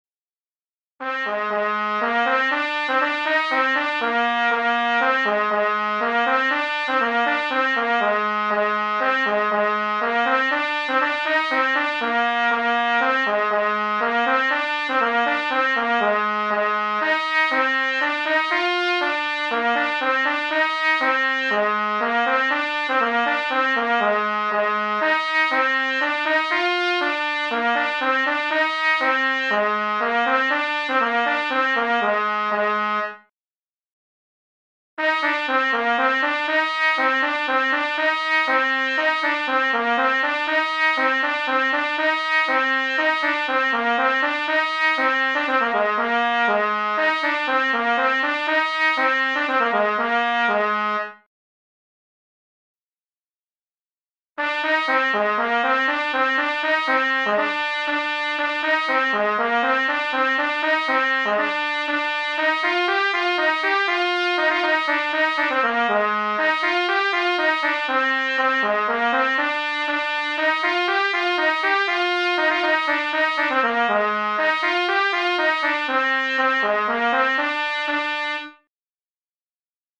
C'est une formation musicale typique de la Bretagne, composée de trois pupitres (cornemuses, bombardes et percussions), créée à la fin de la seconde guerre mondiale en s'inspirant du pipe band écossais qui associe cornemuses et caisses claires.
Notre bagad
Koroll Tro Leur, The Green Hills of Tyrol, When The Battle's Over (airs à marcher), Hanter Dro, An Dro, Kost ar c'hoat, Pach Pi, Kas a Barh (airs à danser), The Water is wide, The Bells of Dumblane, Highland Cathedral, Amazing Grace, My home in the Hills (airs écossais)